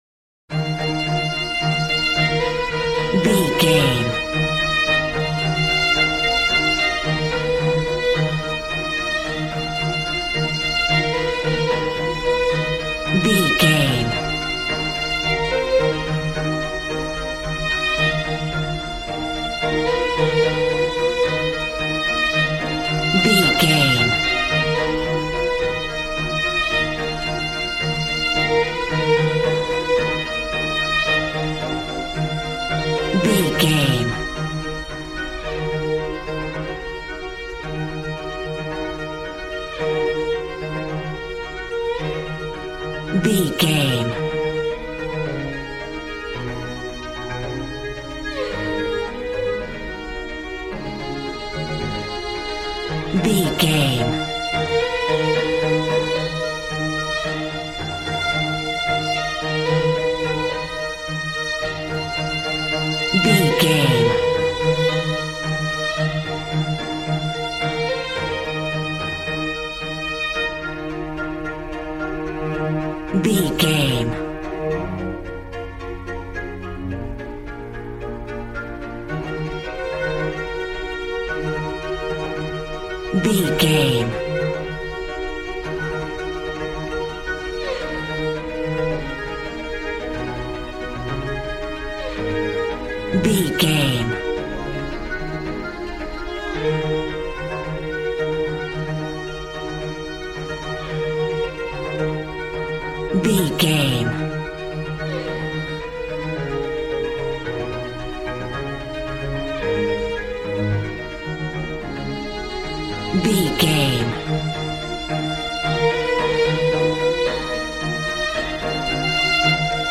Modern film strings for romantic love themes.
Regal and romantic, a classy piece of classical music.
Aeolian/Minor
E♭
regal
cello
violin
brass